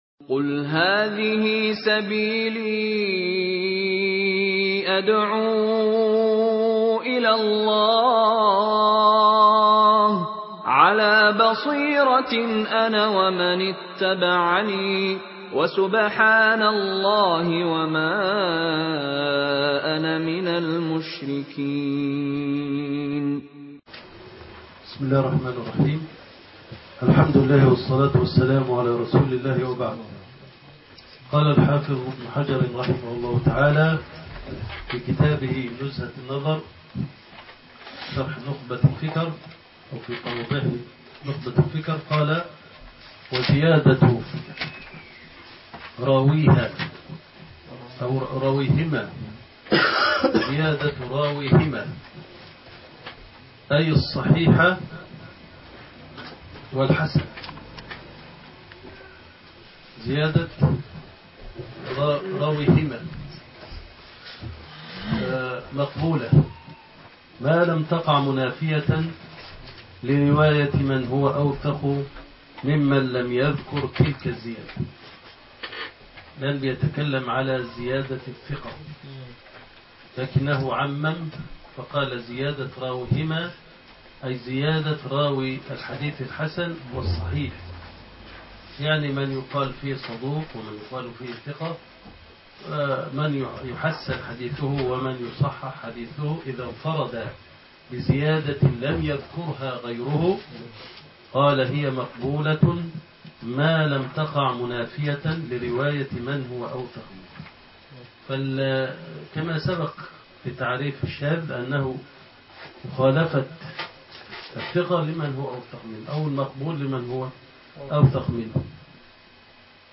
الزياده في الرواية يشكك في الراوي(نزهة الفكر7)محاضرات السنة الأولي من معهد الفرقان - قسم المنوعات